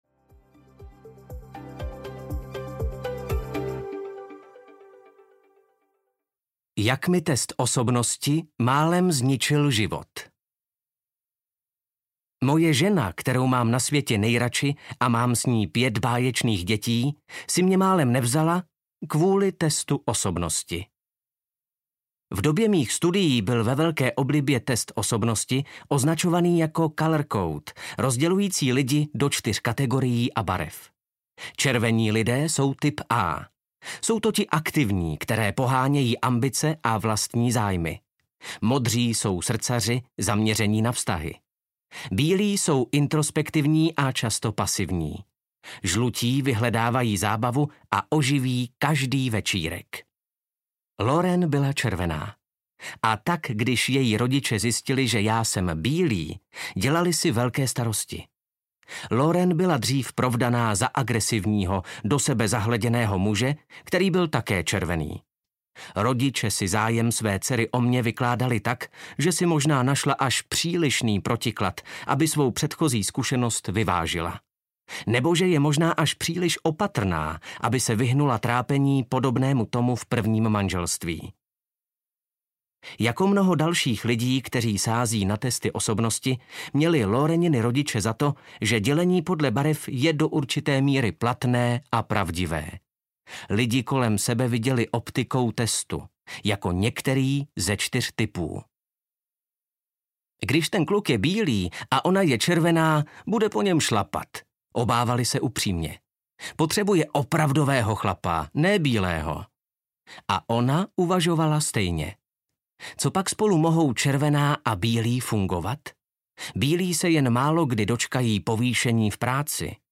Přepište svůj příběh audiokniha
Ukázka z knihy